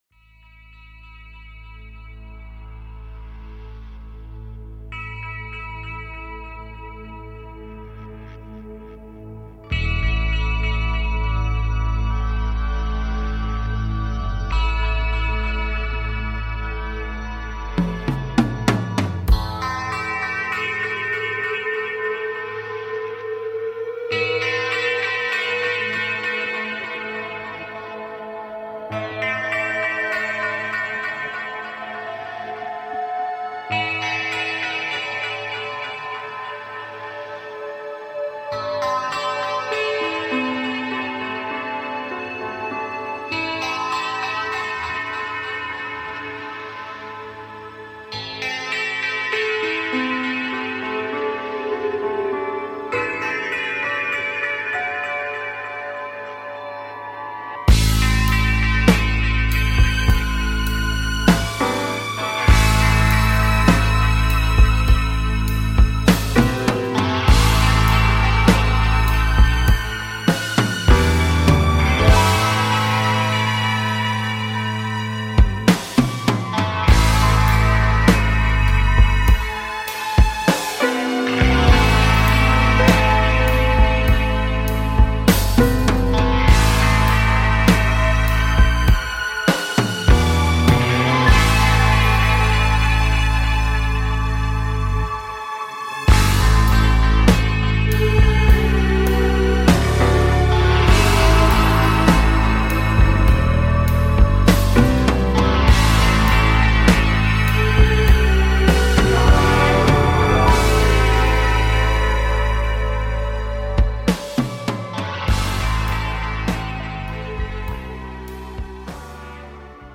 CLICK HERE SUBSCRIBE TO TALK SHOW